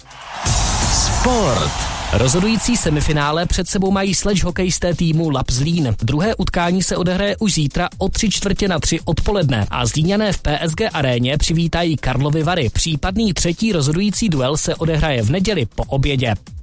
Sportovní zprávy rádia Kiss Publikum.